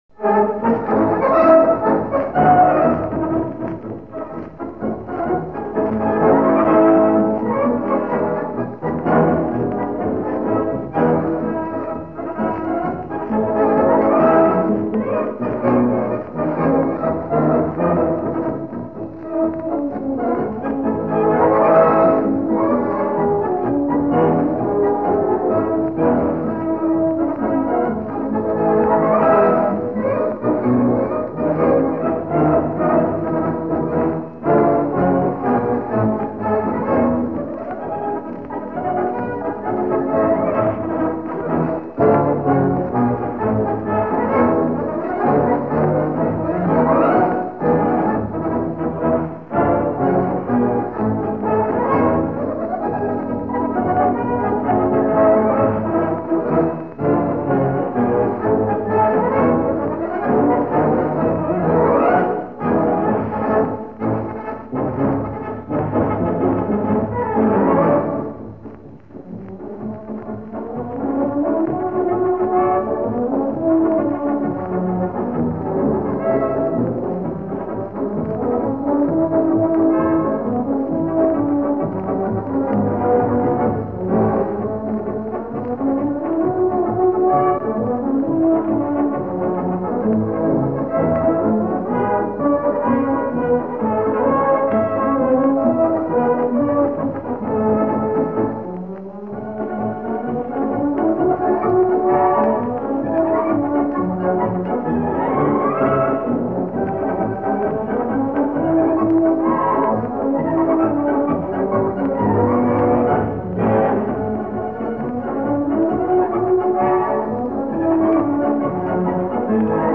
WW2 German music